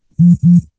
vibration.ogg